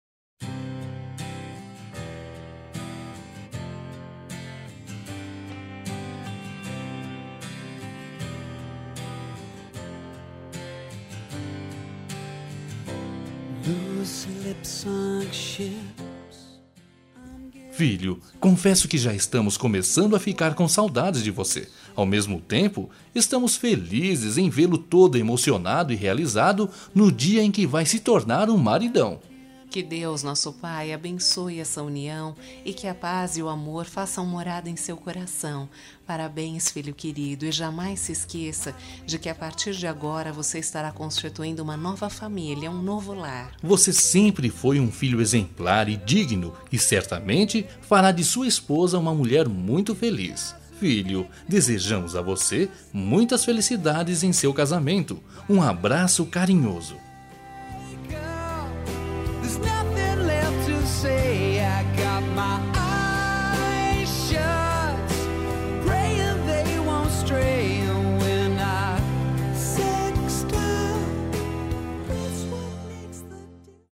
Duas Vozes